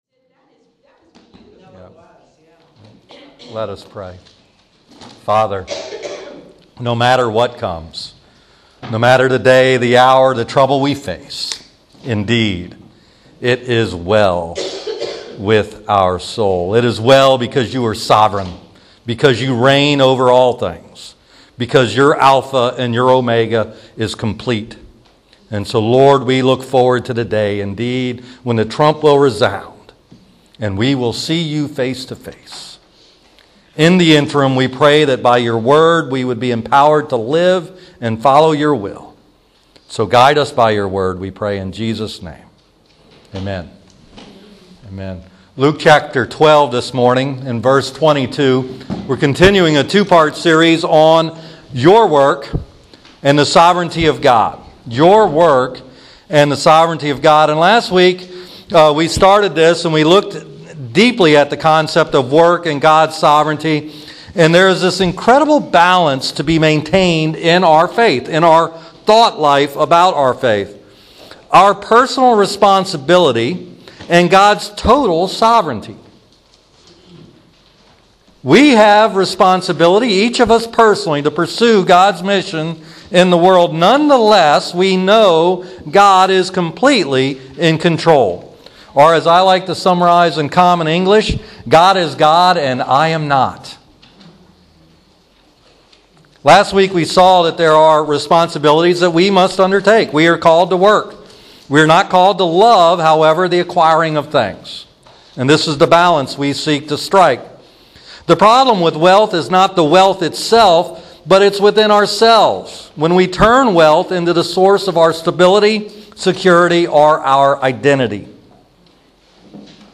It's been called the "Sermon on the Amount" but this annual stewardship tied in nicely with Jesus' sermon on "Do Not Worry".